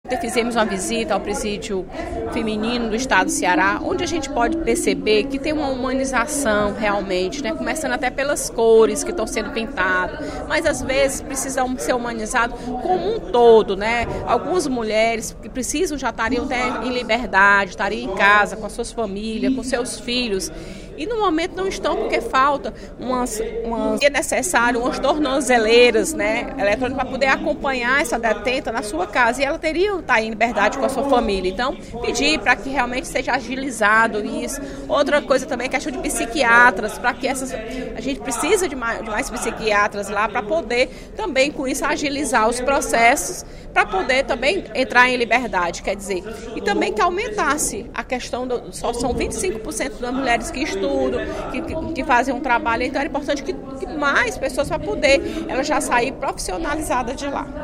A deputada Fernanda Pessoa (PR) registrou, durante o primeiro expediente da sessão plenária desta terça-feira (06/08), a visita da Frente Parlamentar em Defesa da Mulher ao Instituto Penal Feminino Auri Moura Costa, ontem.